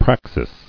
[prax·is]